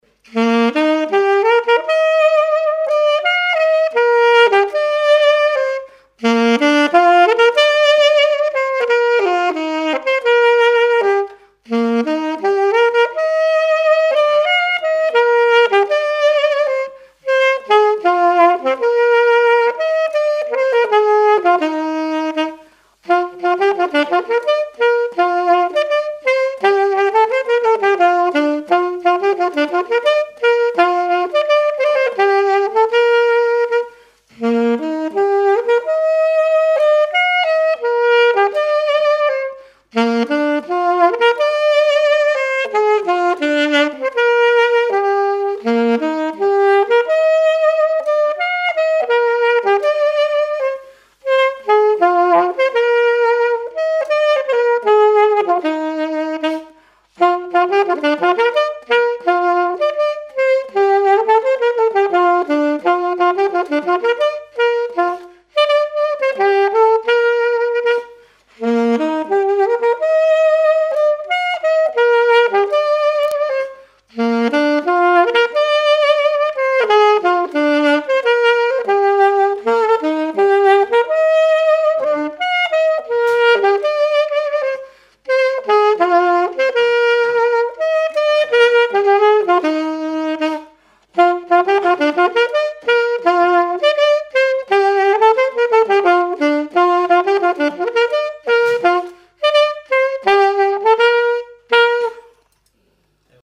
Marche
Genre brève
témoignages et instrumentaux
Catégorie Pièce musicale inédite